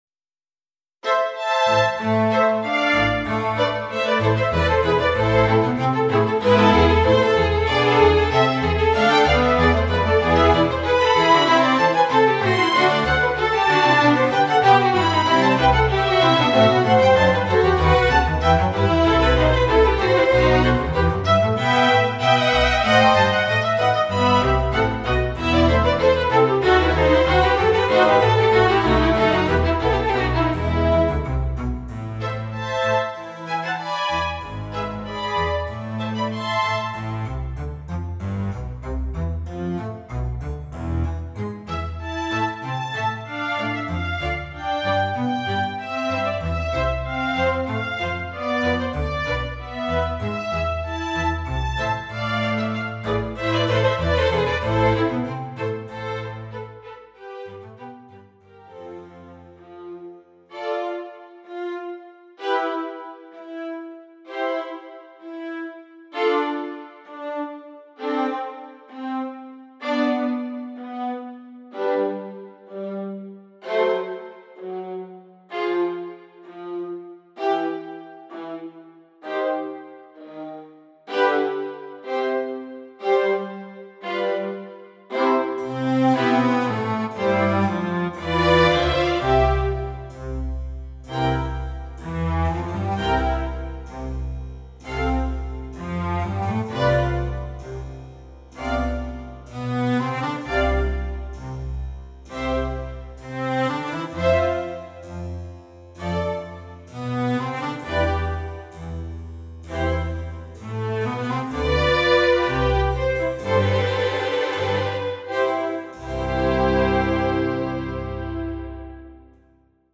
Yet again, this example shall show that the Chambers still are a good value even if you need to play Baroque Music.
Excerpts: Allegro Moderato / Andante (style wished by customer) / Allegro Assai
Libraries: Chamber Strings / Harpsichord (both VSL)
The realism achieved is amazing.
A nice reverb when you just need some tail
The celli soli sounded a bit too uniform and a little MIDI-ish.
3. This performance here has no tempo variation because the end user will do the variations himself.